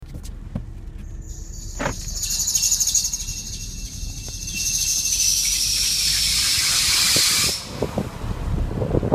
Lautes, kreischendes, quietschen am Anfahren (Audio vorhanden)
ich habe einen Opel Meriva B, Baujahr 2012, Automatik.
Das Geräusch trifft nur auf, wenn der Wagen über Nacht stand. Anlassen geht ohne Probleme und sobald ich Anfahre, kommt folgendes Geräusch:
Dann rutscht der durch, und das quietscht.